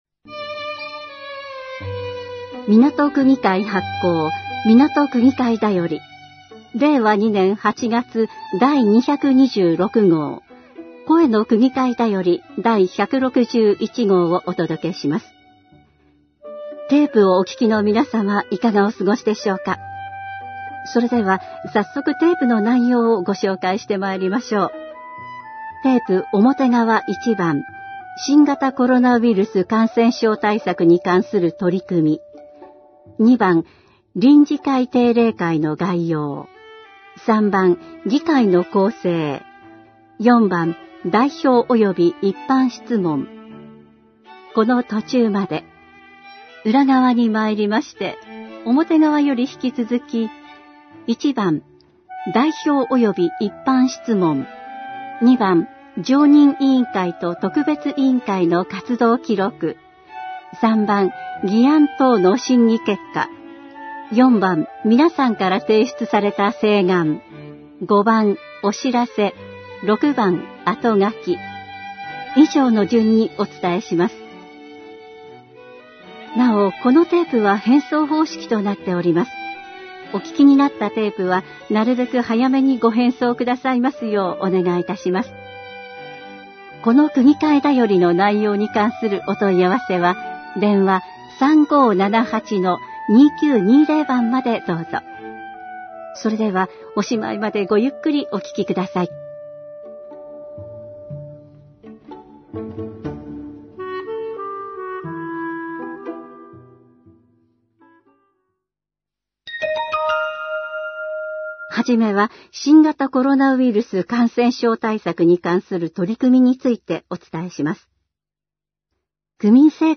みなと区議会だよりNo.226号から、「声の区議会だより」を掲載しています。掲載している音声ファイルは、カセットテープで提供している音声ファイルをそのまま掲載しています。そのため、音声の冒頭で「テープの裏側にまいりました」のような説明が入っています。